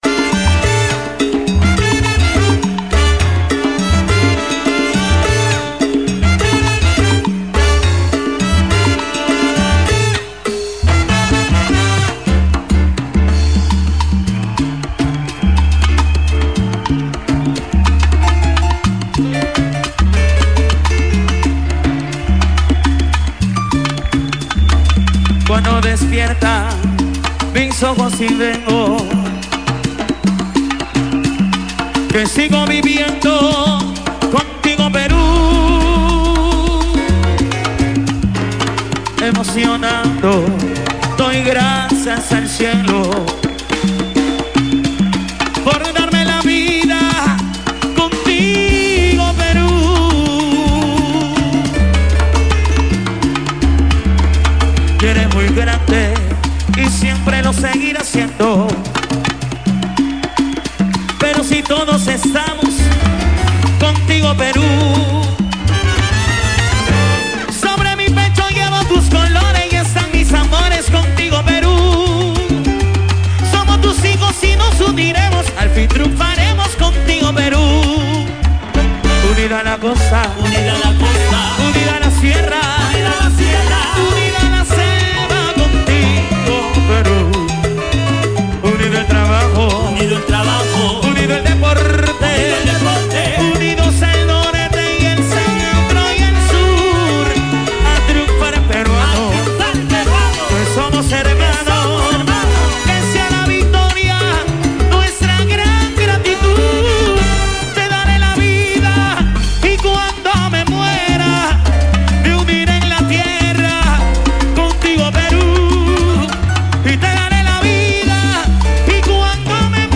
versión en salsa
*Asimismo, pude grabar la tanda comercial radial de ese día.